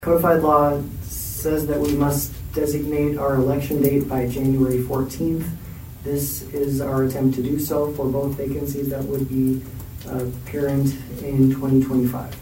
ABERDEEN, S.D.(HubCityRadio)- At Monday’s Aberdeen City Council meeting, the council released the official date for the upcoming elections for two city council seats.